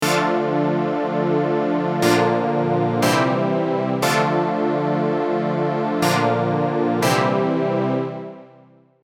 I have recorded a simple pattern using the ES2 synthesizer.
Here is the original synth line.
Original_Synth.mp3